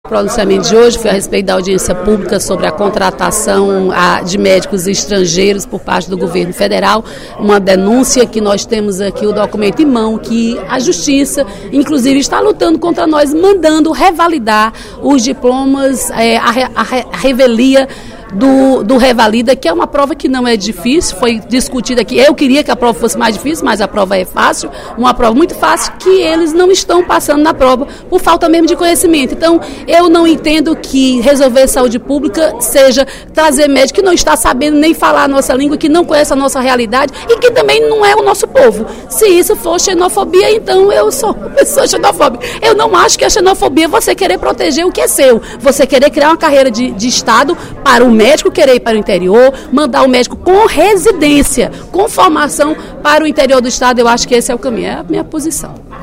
A deputada Dra. Silvana (PMDB) destacou, durante o primeiro expediente da sessão plenária desta sexta-feira (14/06), a audiência pública que discutiu a situação da aplicação do Exame Nacional de Revalidação de Diplomas Médicos, o Revalida, para profissionais estrangeiros que pretendem exercer a profissão no Brasil.